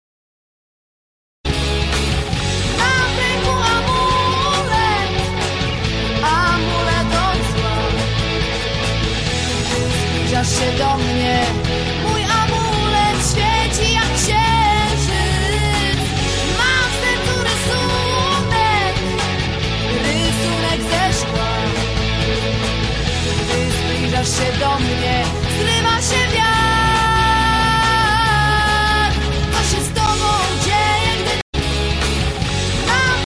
w Rock / Metal
Głos Przypomina mi Trochę Korę lub Małgorzatę Ostrowską ma charakterystyczny głos